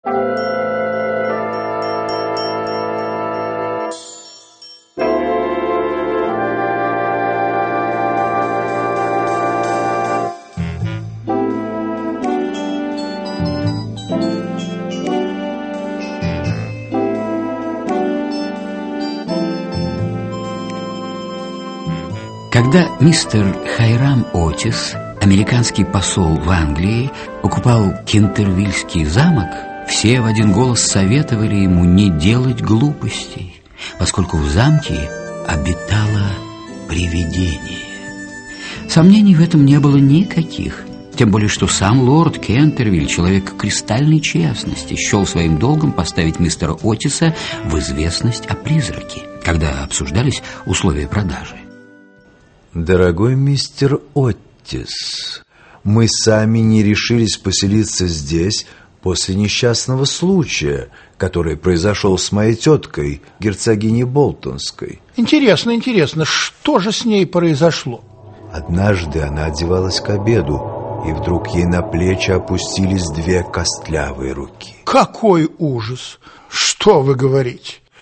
Аудиокнига Кентервильское привидение (спектакль) | Библиотека аудиокниг
Aудиокнига Кентервильское привидение (спектакль) Автор Оскар Уайльд Читает аудиокнигу Александр Леньков.